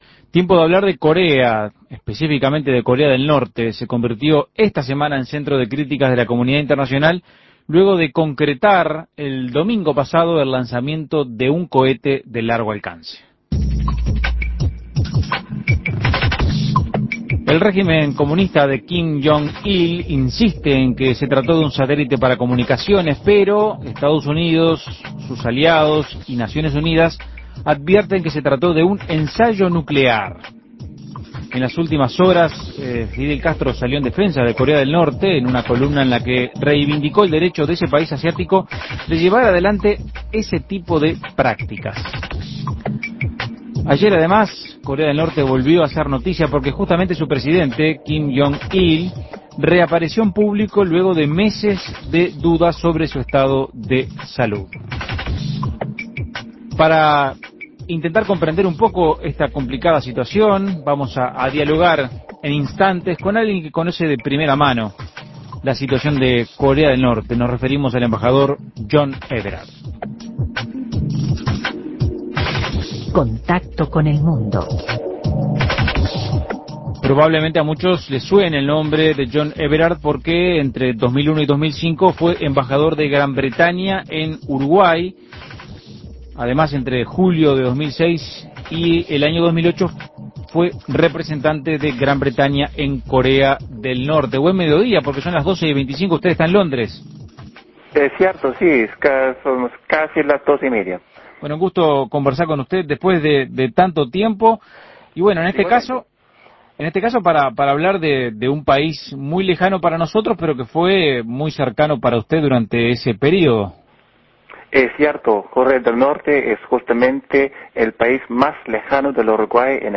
Escuche el contacto con John Everard, ex embajador en Uruguay y Corea del Norte